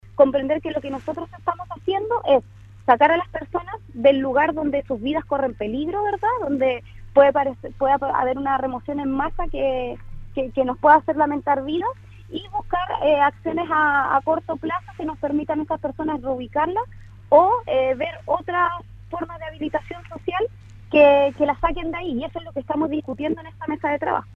Durante esta mañana la Delegada Presidencial de la Región de Los Lagos Giovanna Moreira en conversación con Radio Sago se refirió al Plan de Emergencia Habitacional que encabeza el Gobierno para abordar el traslado de las familias que se encuentran en la Ladera Pelluco, en terrenos de la Empresa de Ferrocarriles del Estado en Puerto Montt y que días atrás producto de un sistema frontal registró un derrumbe importante con el riesgo para las familias allí habitan.